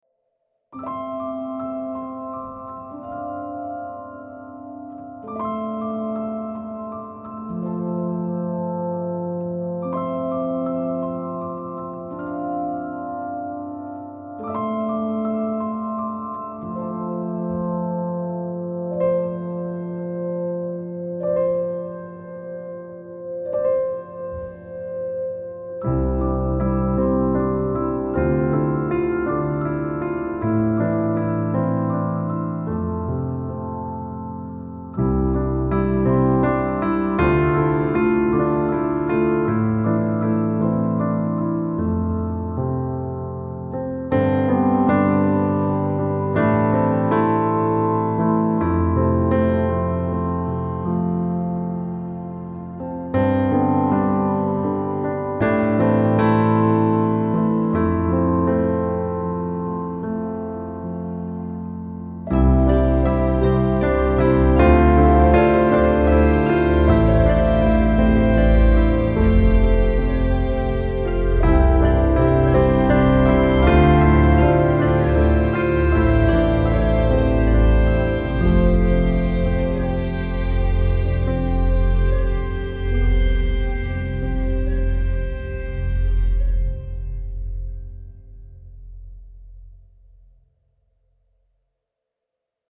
Largo [0-10] - - - -